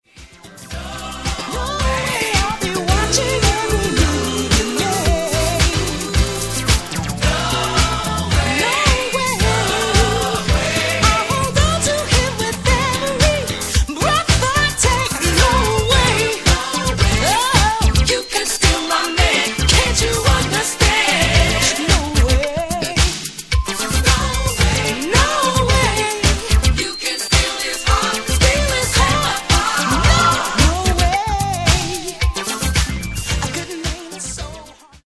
Genre:   Disco Soul